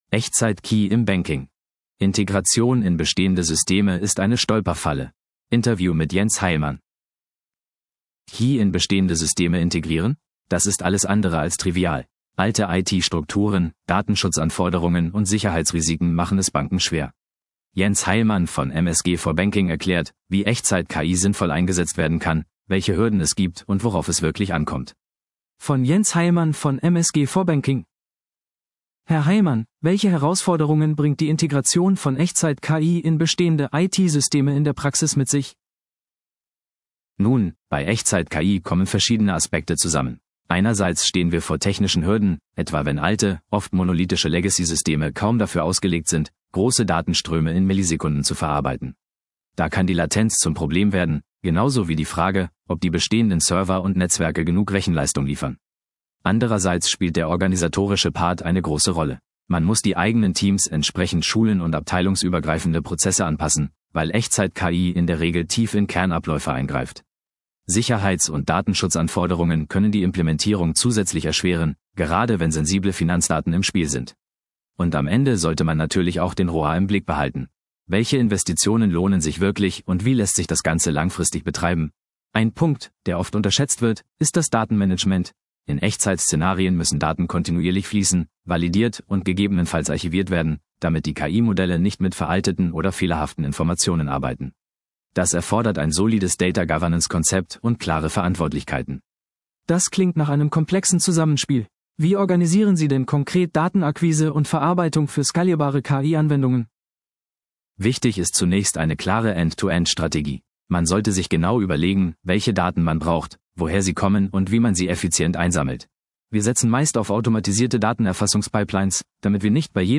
Interview: Echte KI-Anwendungen und deren IT-Anbindung